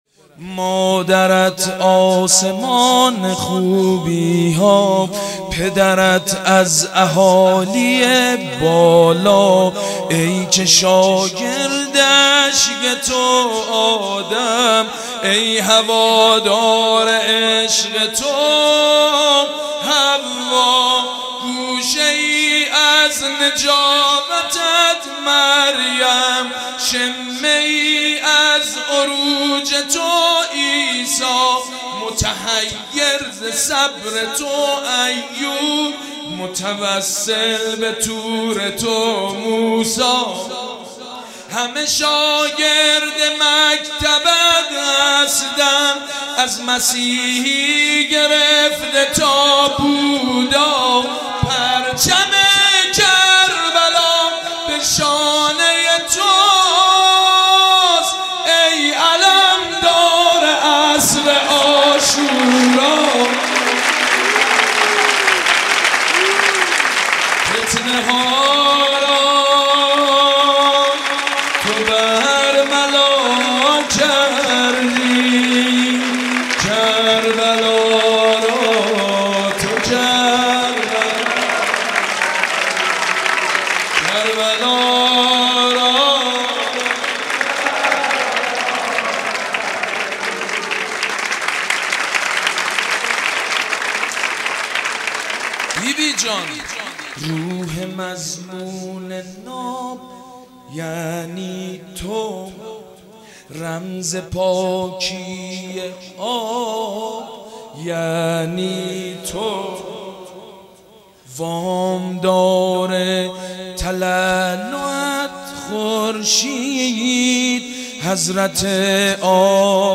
دانلود ولادت حضرت زینب مولودی سید مجید بنی فاطمه اخبار مرتبط دعای روز نهم ماه رمضان مسیر حرکت هیئت ها به سمت دیسکو !